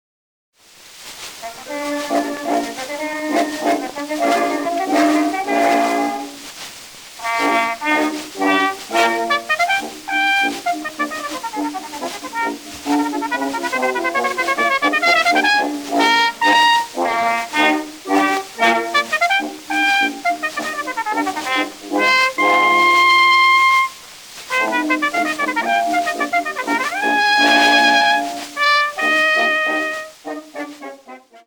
CORNET
original double-sided recordings made 1900-1922